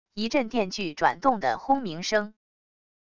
一阵电锯转动的轰鸣声wav音频